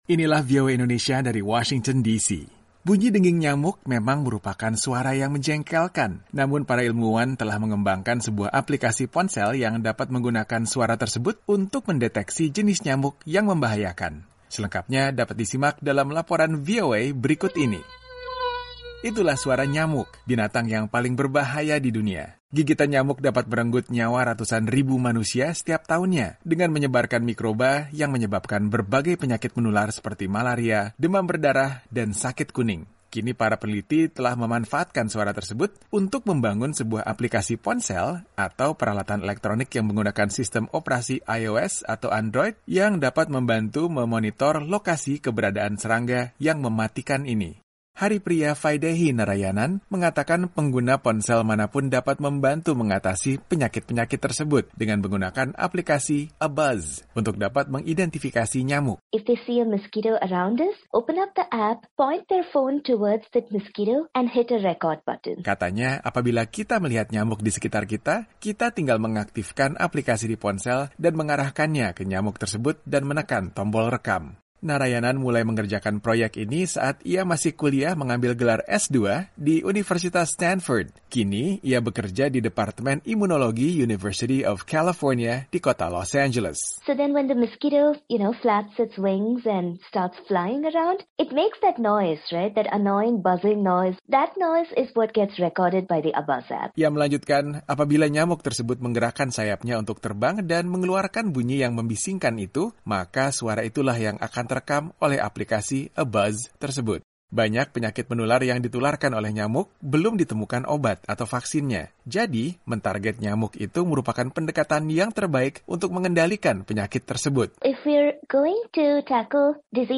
Namun, para ilmuwan telah mengembangkan aplikasi ponsel yang dapat menggunakan suara tersebut untuk mendeteksi jenis nyamuk yang membahayakan. Selengkapnya dapat disimak dalam laporan VOA berikut ini.